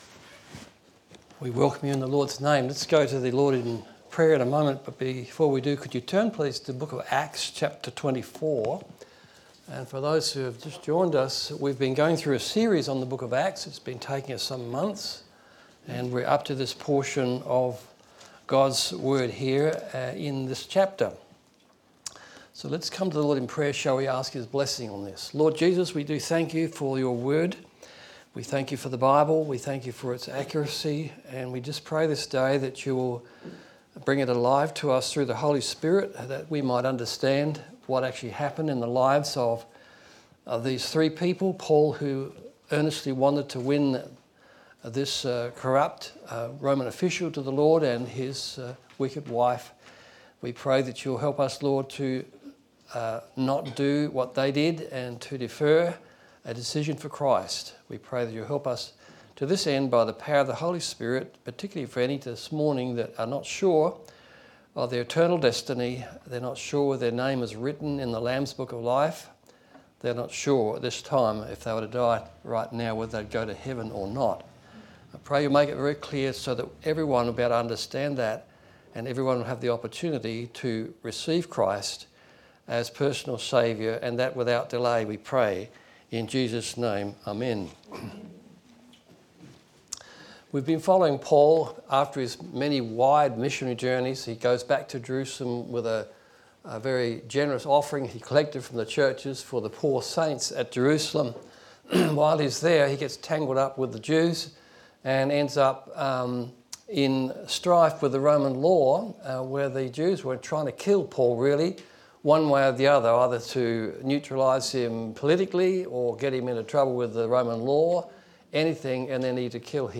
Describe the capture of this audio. Series: Acts Of The Apostles Service Type: Sunday Morning